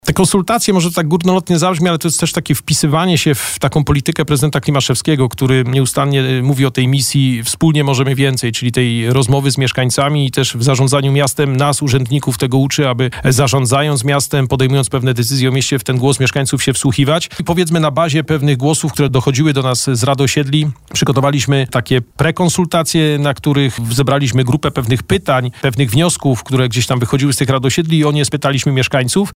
Tzn. zmiana finalnie wyszła taka, że mieszkańcy od przyszłego roku – od kwietnia do listopada – dostaną 15 worków i będą mogli w dowolny sposób, tak jak gospodarują u siebie na nieruchomości, te odpady zielone podać – objaśniał na naszej antenie wiceprezydent Bielska-Białej, Adam Ruśniak